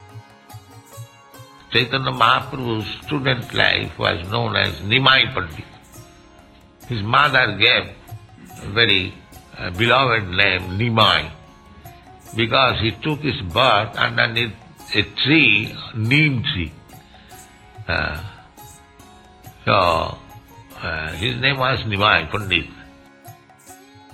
(740108 - Lecture SB 01.16.11 - Los Angeles)